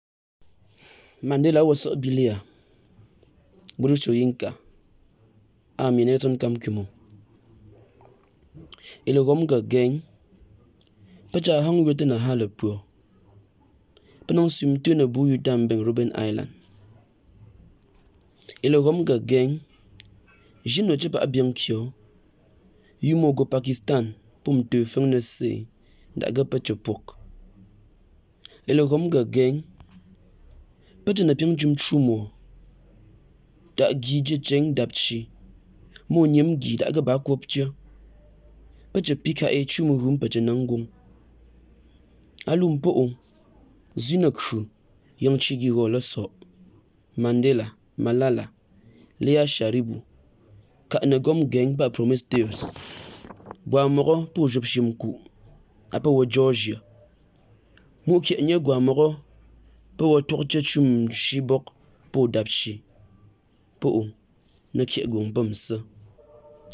A reading of the poem in Ghomala